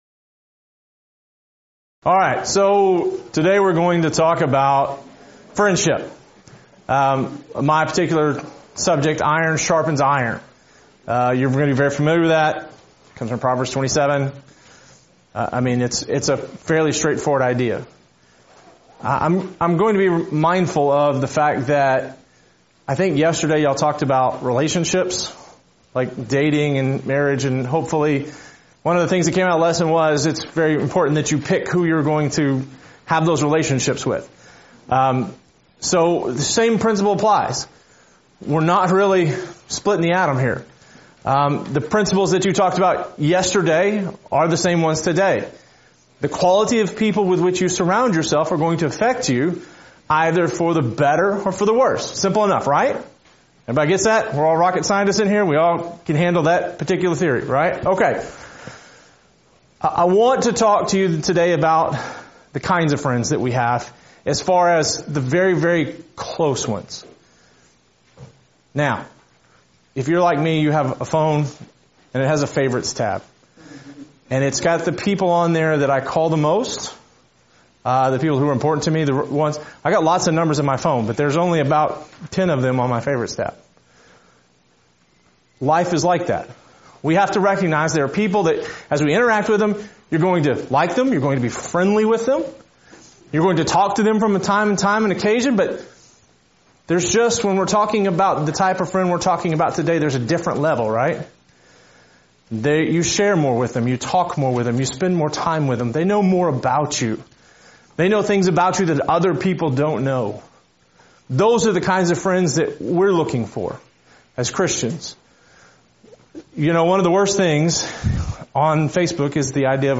Event: 2018 Focal Point Theme/Title: Preacher's Workshop
lecture